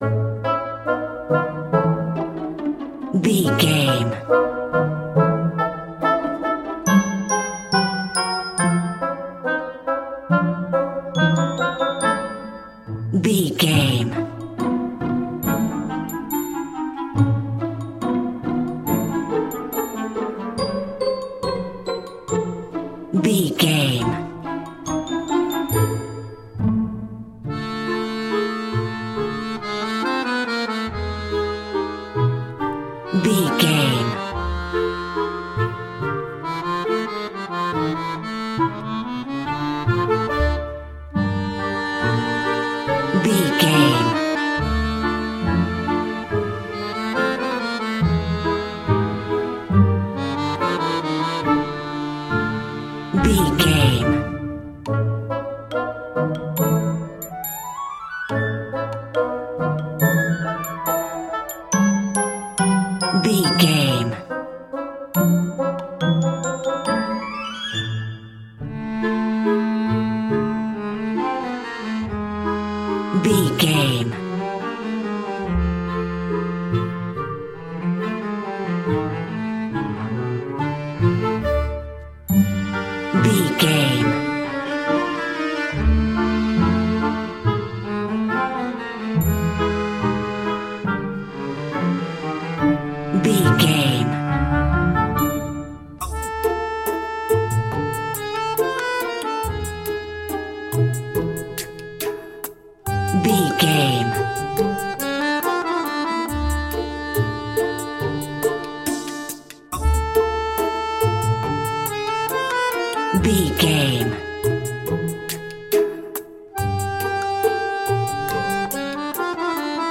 Uplifting
Aeolian/Minor
Slow
percussion
flutes
piano
orchestra
double bass
accordion
silly
circus
goofy
comical
cheerful
perky
Light hearted
quirky